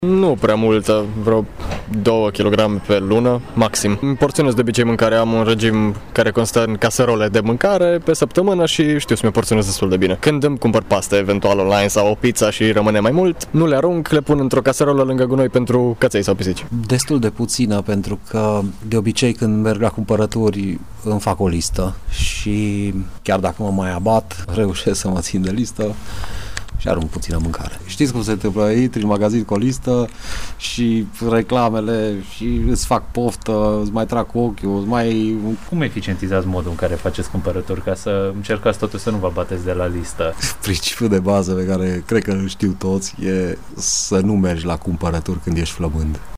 Mureșenii spun că nu risipesc multă mâncare. Unii pentru că merg la cumpărături cu listă, alții pentru că sunt la dietă: